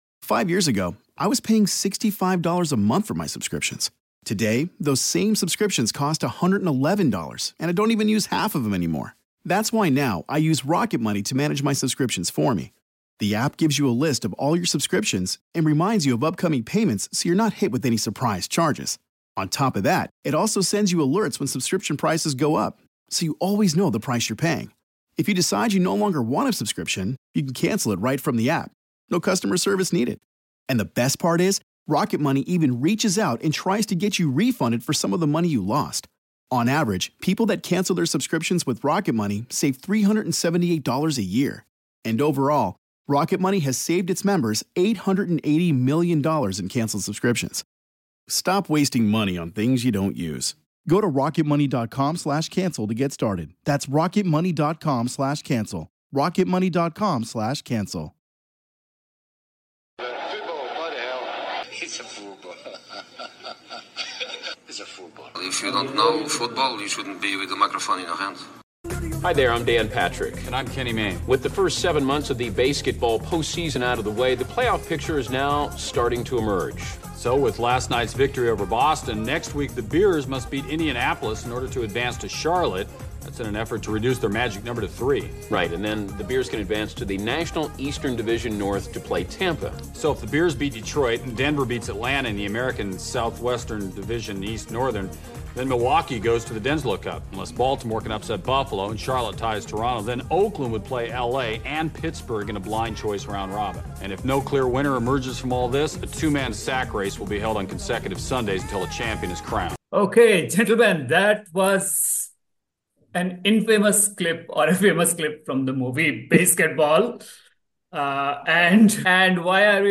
The panel convenes to wrap their heads around the new fangled UEFA Champions League format, and how it resembles March Madness, or even worse, the Baseketball format. What sort of unintended consequences hath UEFA wrought?